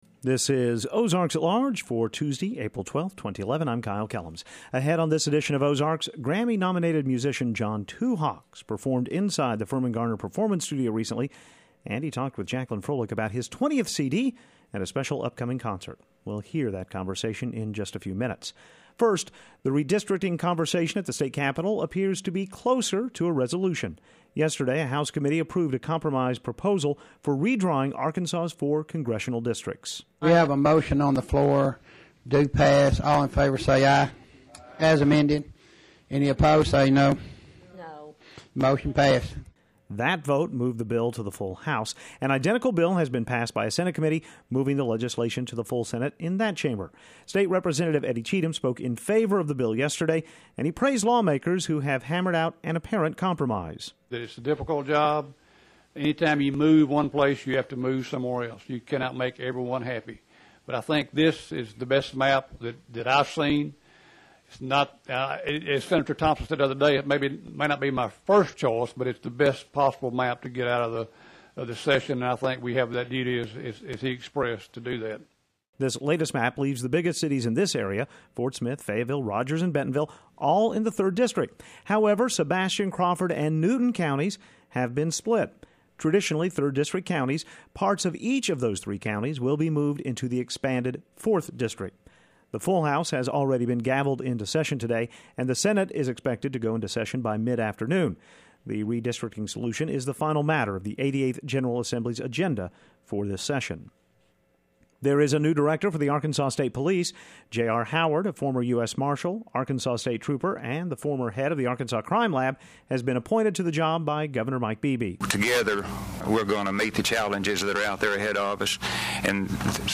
performs in the Firmin-Garner Performance Studio